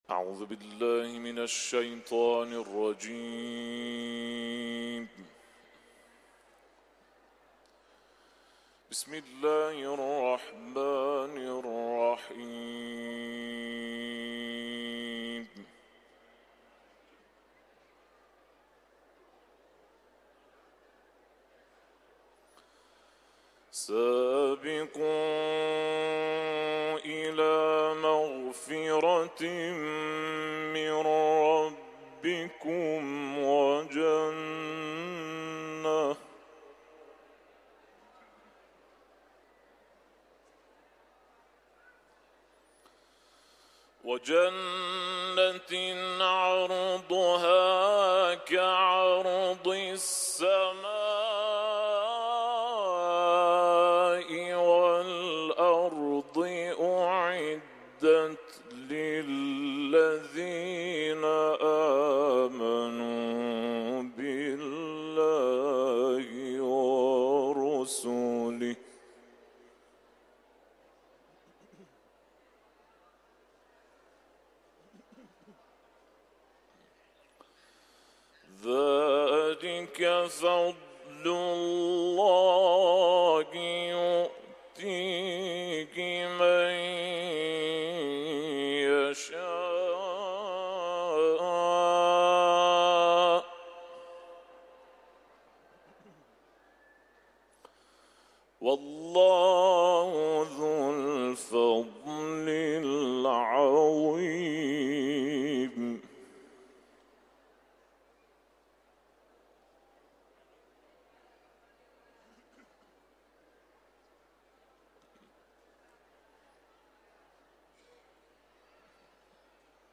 Etiketler: İranlı kâri ، Kuran tilaveti ، Hadid suresi